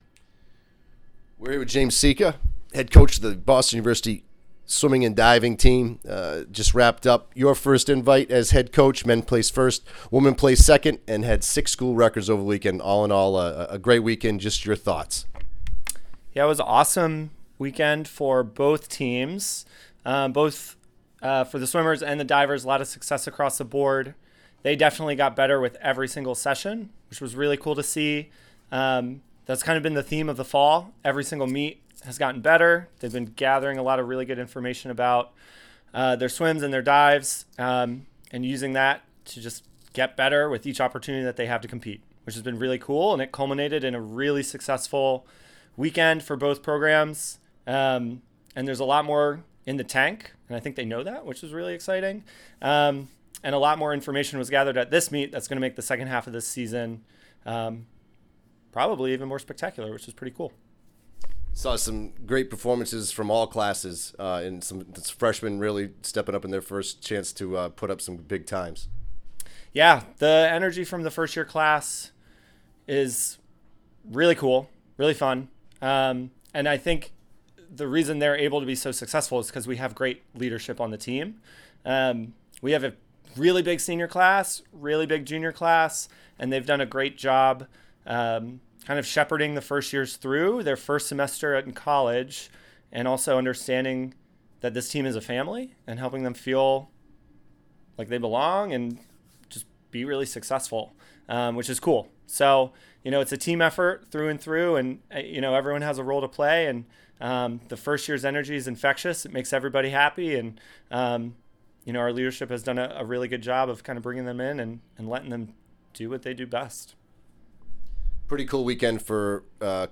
Terrier Invite Postmeet Interview